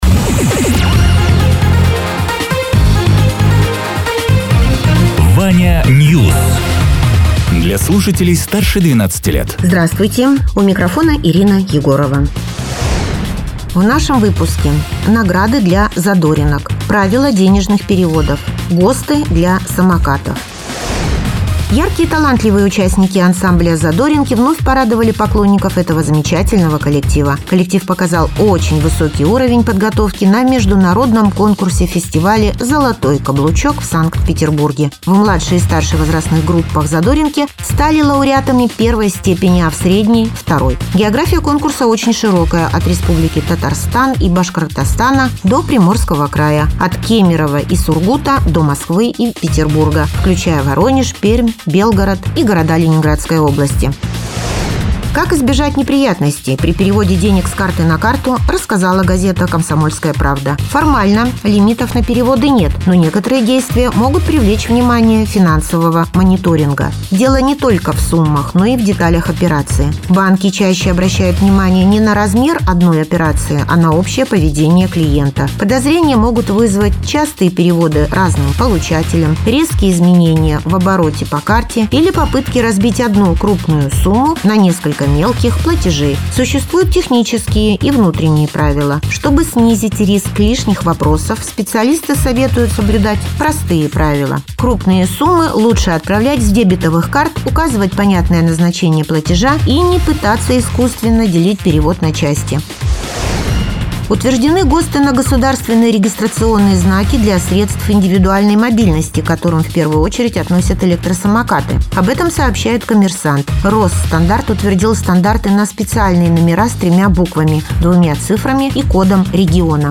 Радио ТЕРА 09.04.2026_12.00_Новости_Соснового_Бора